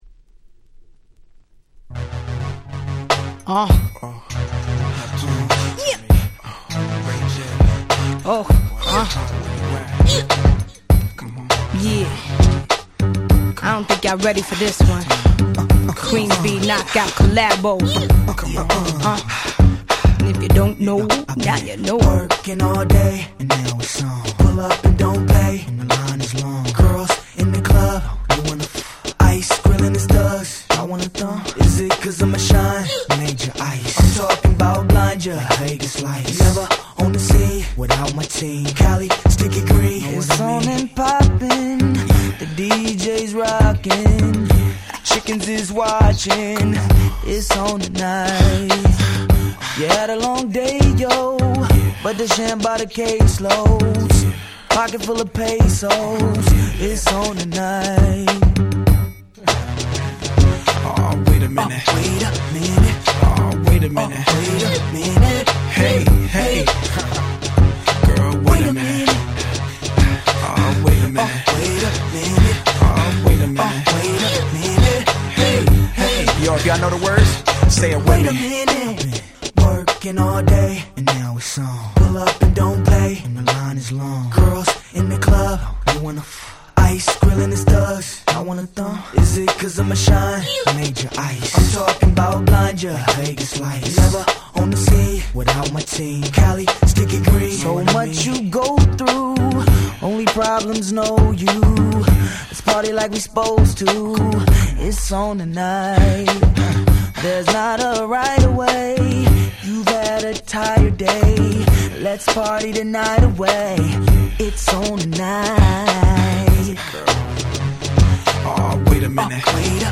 01' Super Hit R&B !!